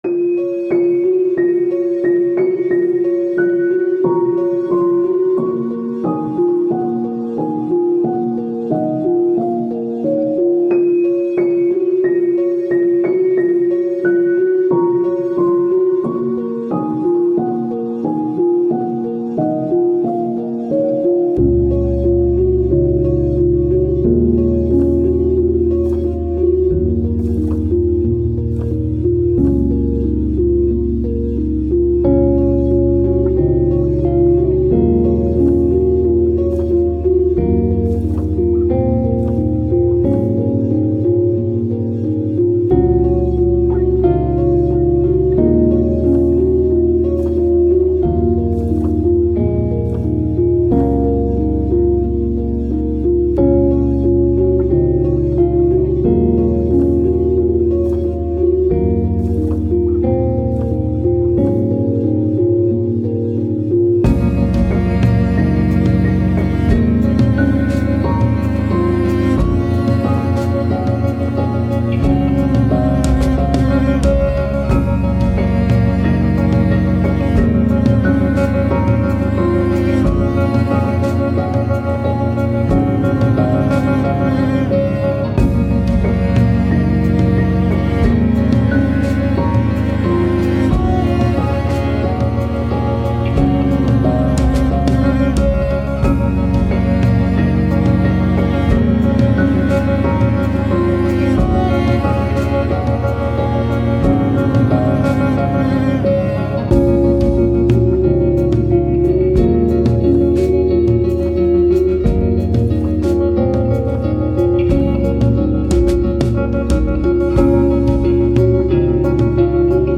Ambient, Soundtrack, Emotive, Sad, Melancholic, Hope